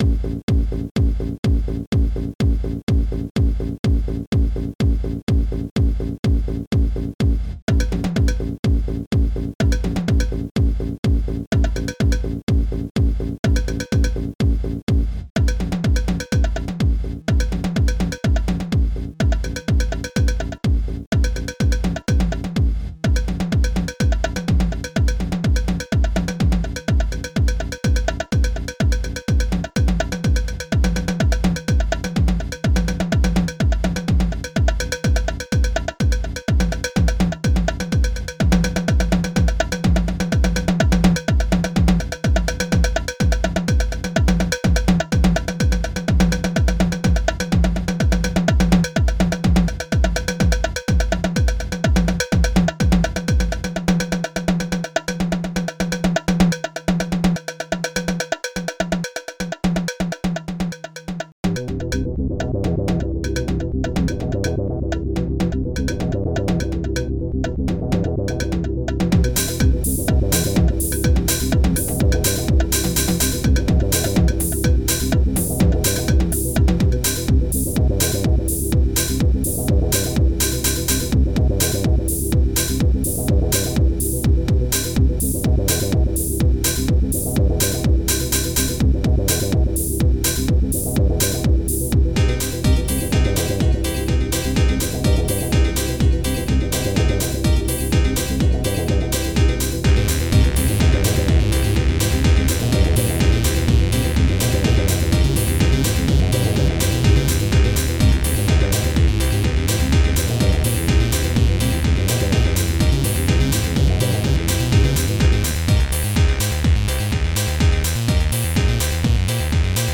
[4:44] 11 Channels